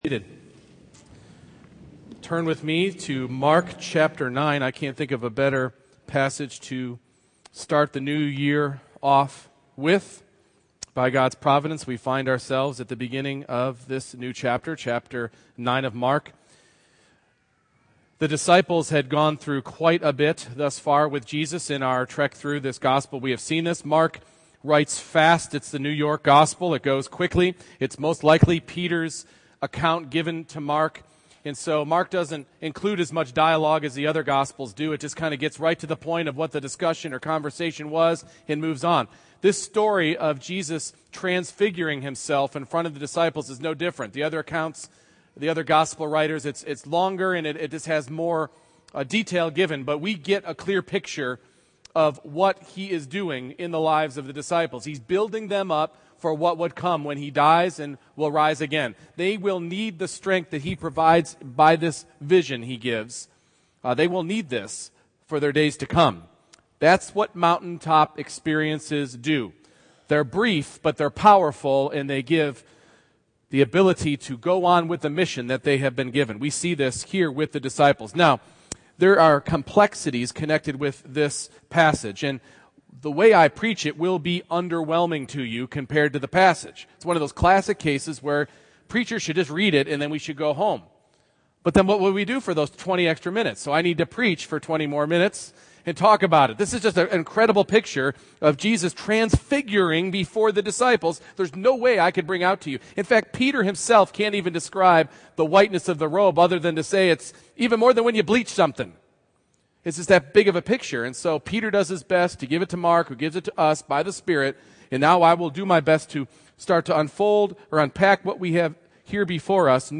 Mark 9:1-13 Service Type: Morning Worship Sharing in Christ’s glory turns to sharing His glory with the world.